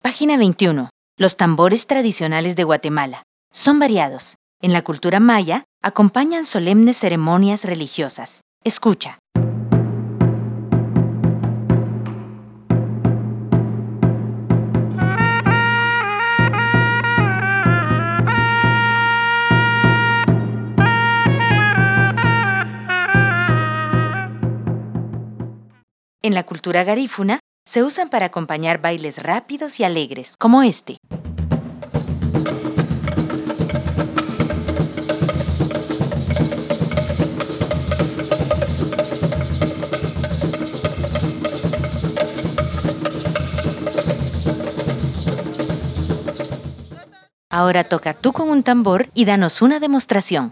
Tambores tradicionales de Guatemala